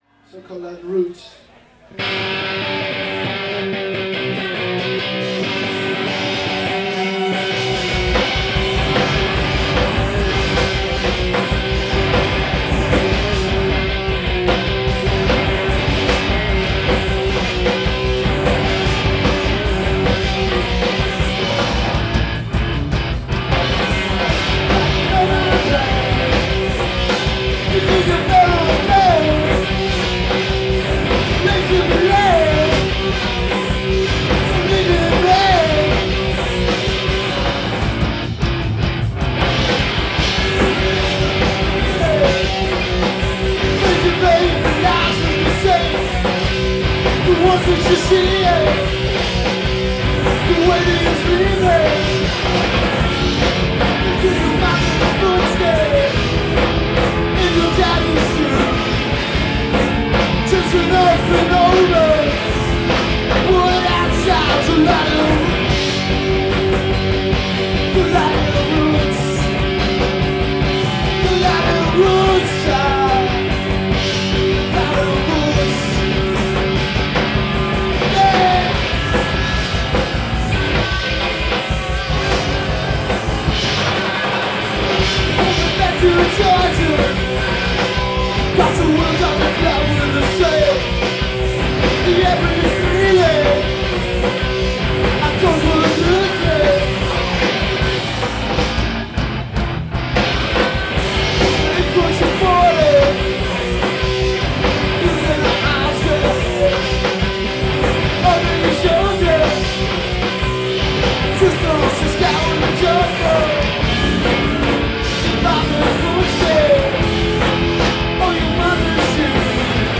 Live at Border
in Burlington, Vermont